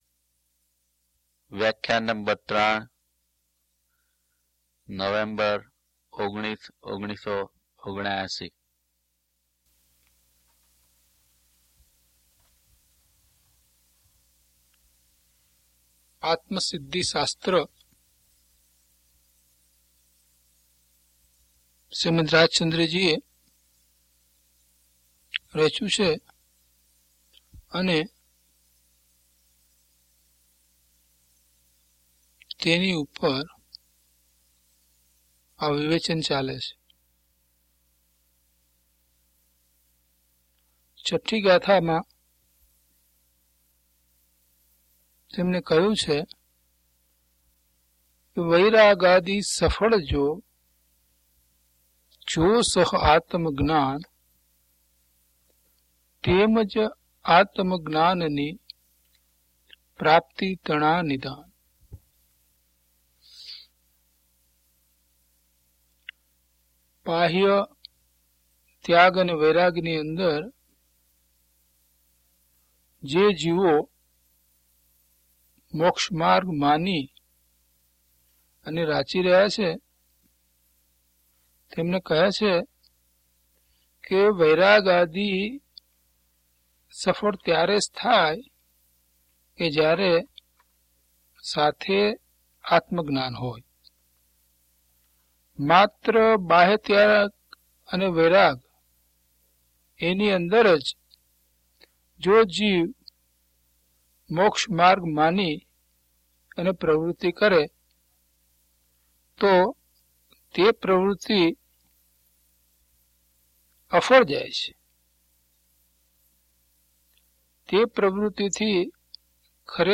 DHP012 Atmasiddhi Vivechan 3 - Pravachan.mp3